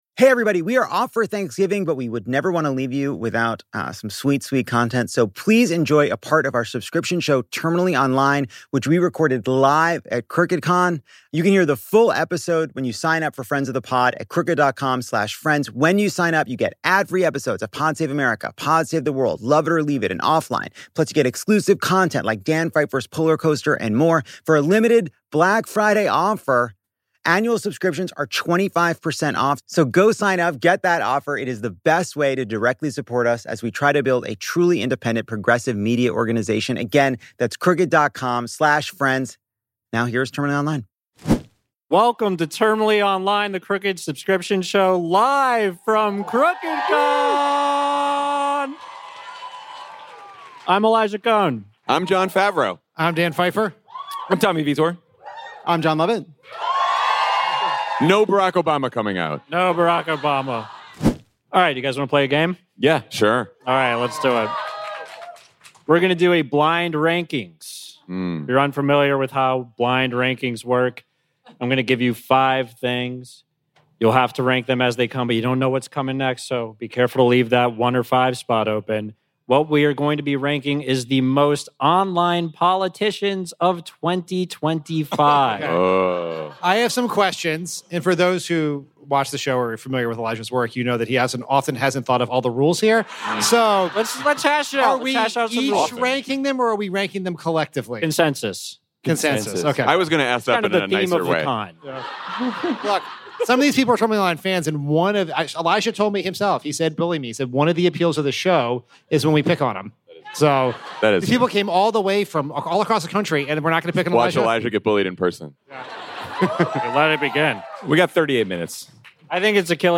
Live from Crooked Con